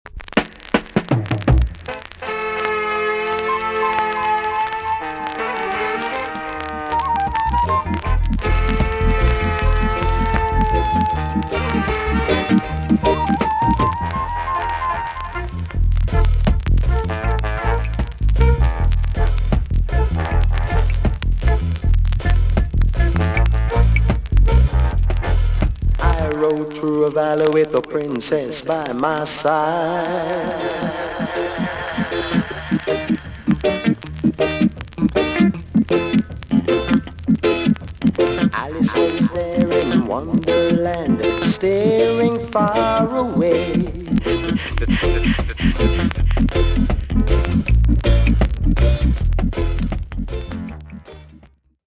TOP >SKA & ROCKSTEADY
VG+~VG ok 軽いチリノイズが入ります。